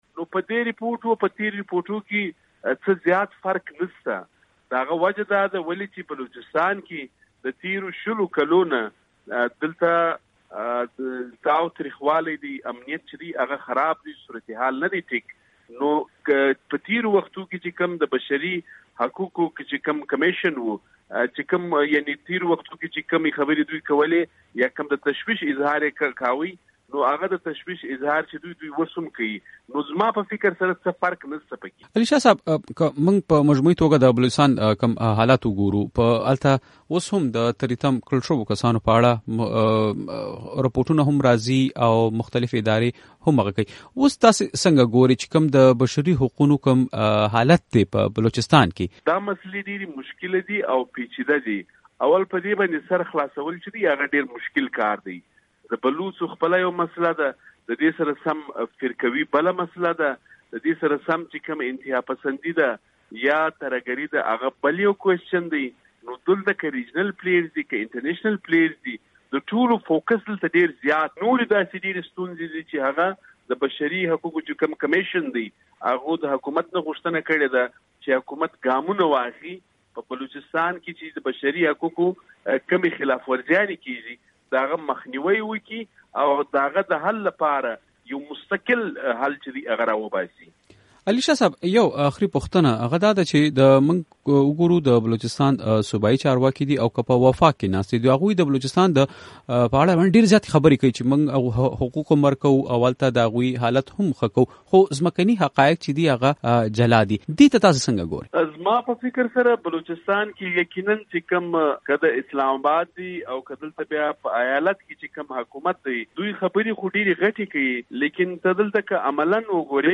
بلوچستان کې د بشري حقونو د وضعيت په اړه مرکه